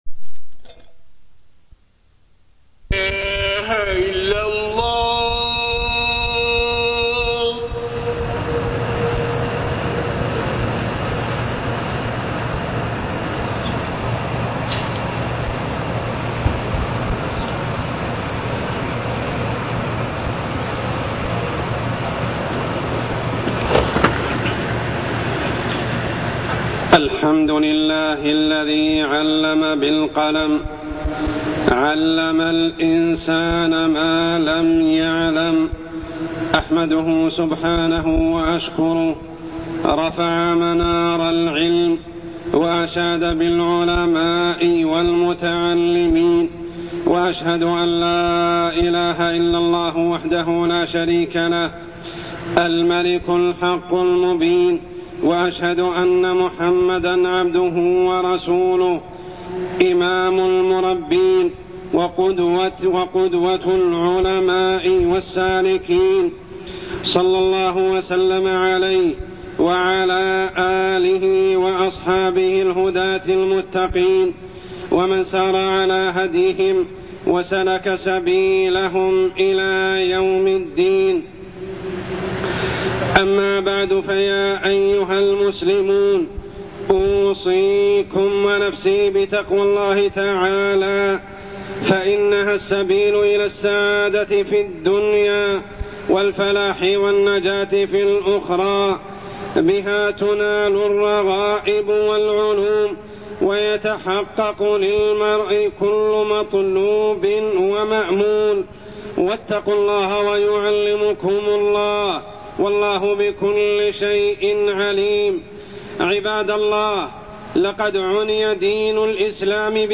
تاريخ النشر ٣ جمادى الآخرة ١٤٢١ هـ المكان: المسجد الحرام الشيخ: عمر السبيل عمر السبيل طلب العلم والمعرفة